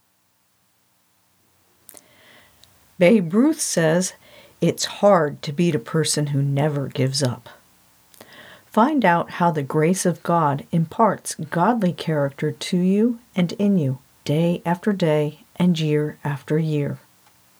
The “cleaned up” version does indeed seem to meet the ACX standard.
And yes the hum seems to be significantly reduced-- well done you found it.